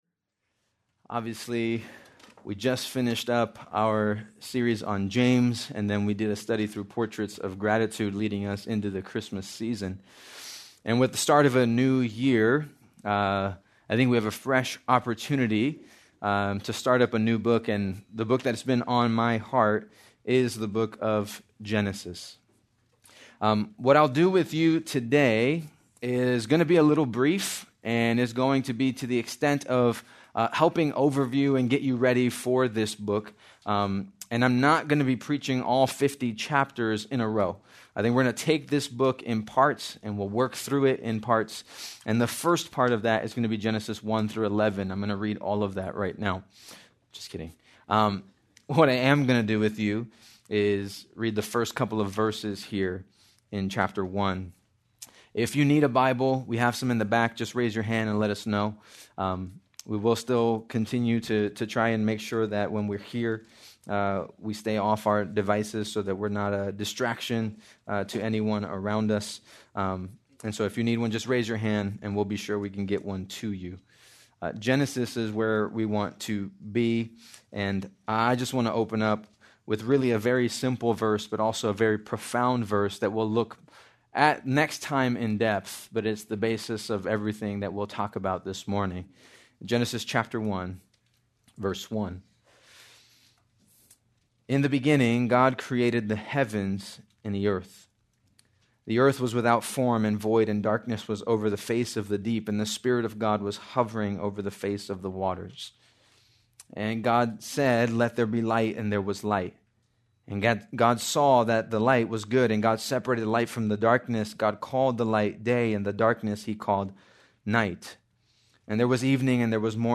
January 4, 2026 - Sermon